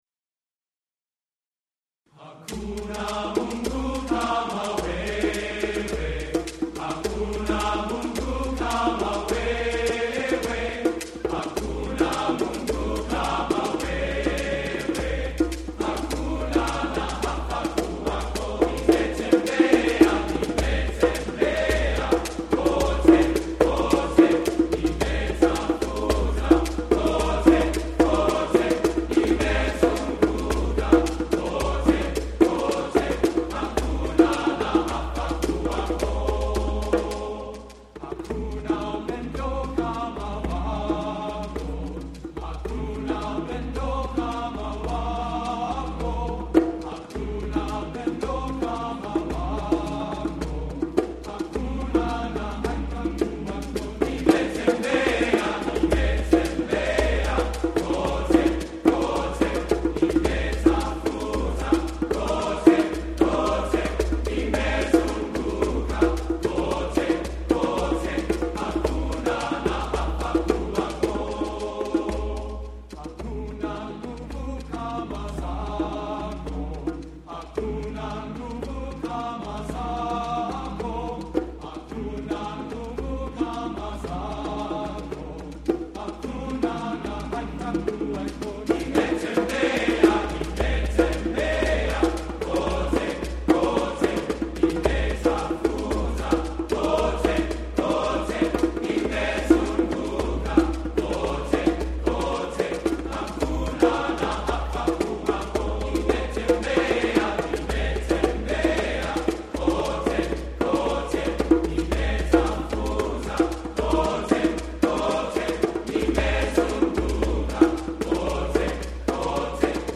Composer: Kenyan Folk Song
Voicing: TTBB a cappella